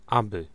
Ääntäminen
US : IPA : /ˈsoʊ/